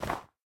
snow4.ogg